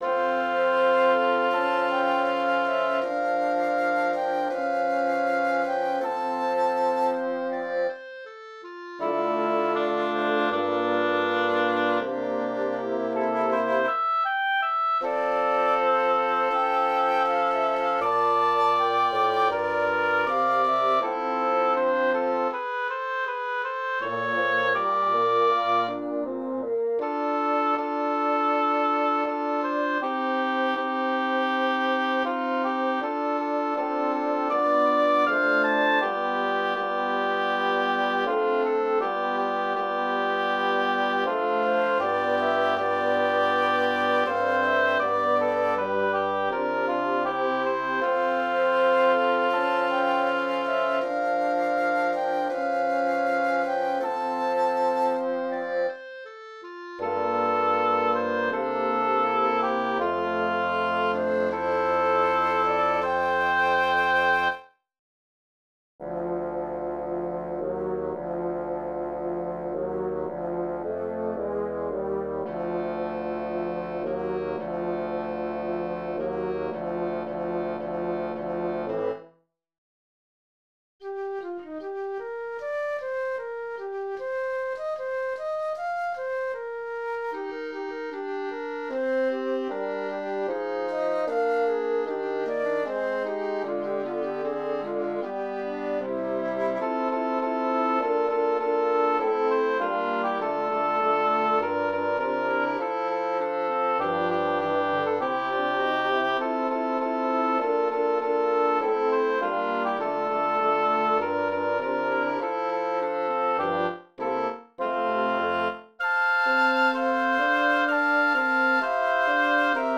Quintette à vent et 1 ou 2 récitants